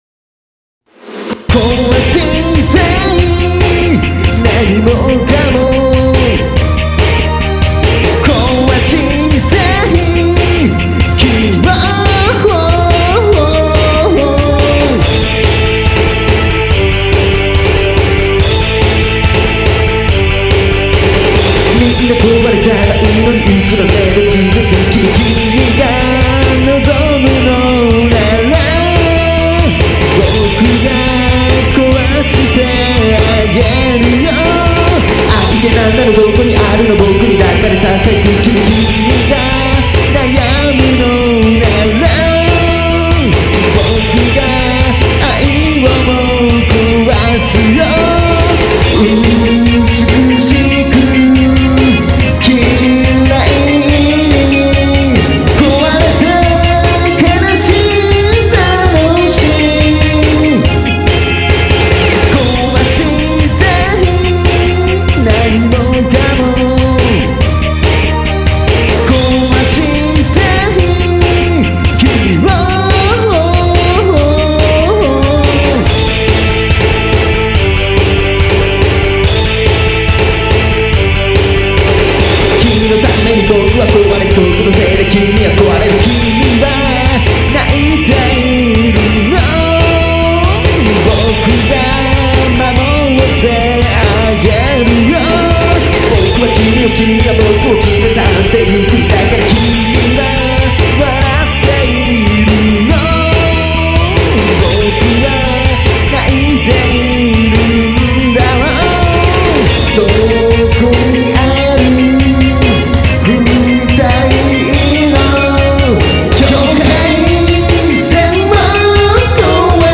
YAMAHA MOTIF ES6
以前からやってみたかったユーロ風味な曲です。
ユーロって真面目にやろうと思ったら難しいのであくまで「風味」で。
ただうるさいだけの曲になった気も(汗)。
マイナー調の曲はいまいち僕には向いてないっす。